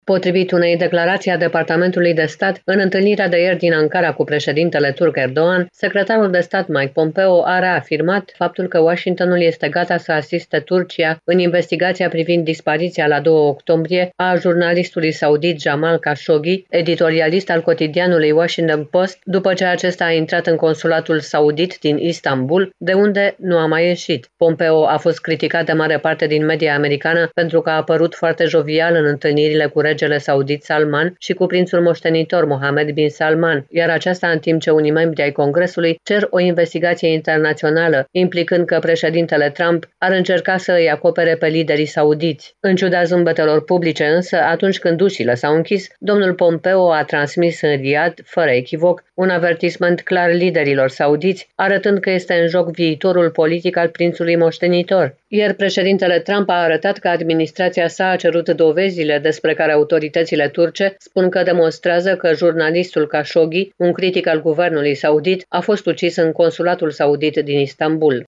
transmite din Washington: